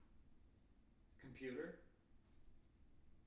wake-word
tng-computer-390.wav